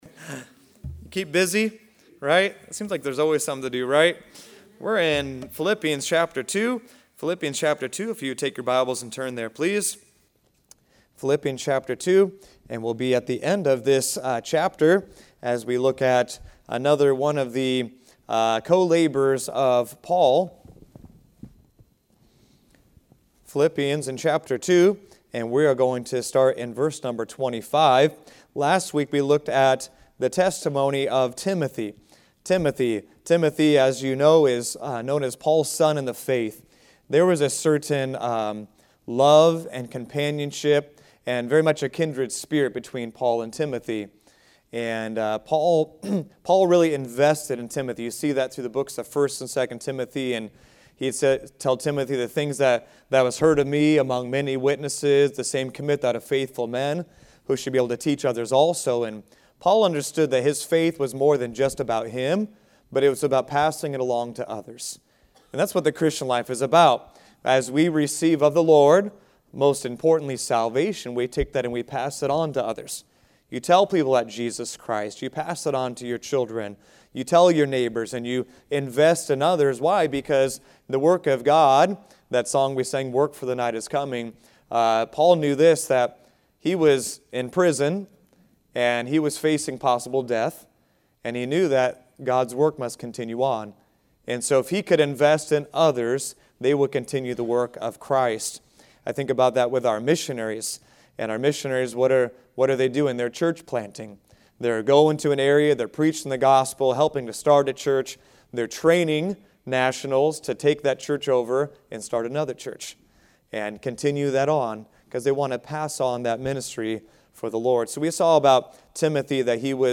Faithful Epaphroditus | Sunday School – Shasta Baptist Church